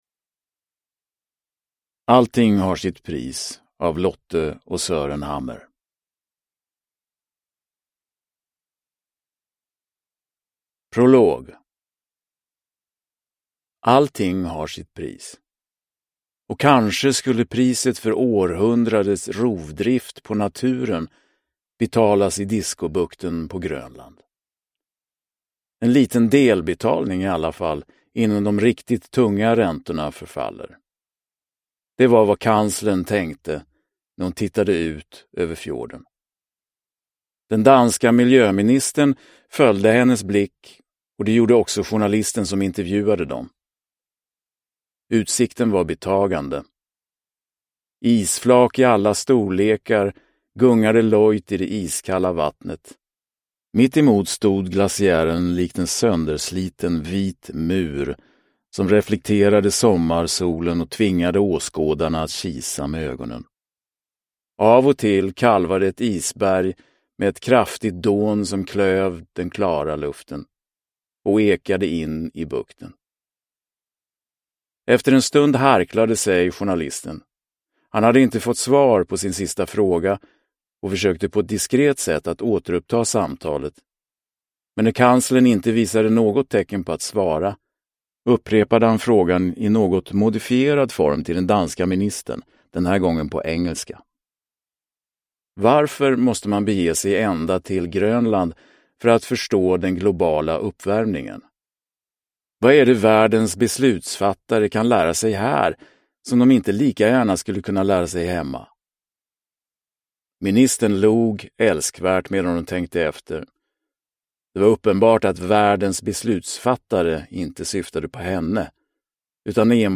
Allting har sitt pris – Ljudbok – Laddas ner